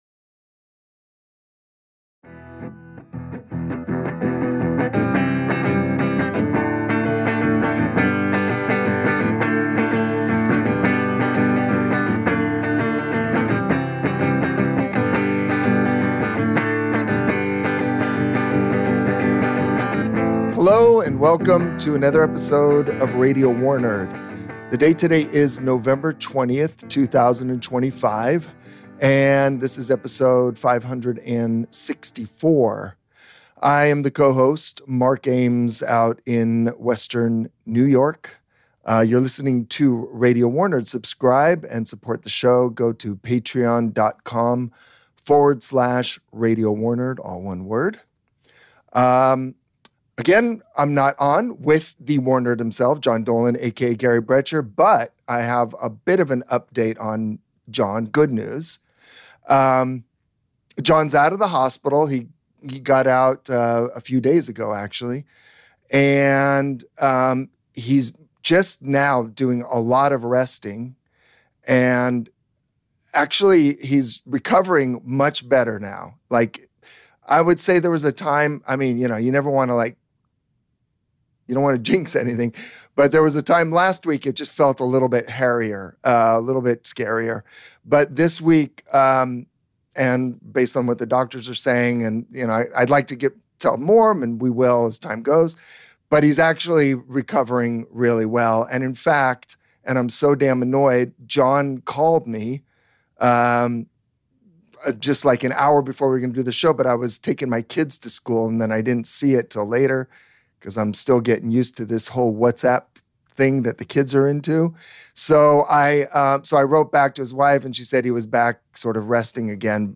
Music interlude
Our interview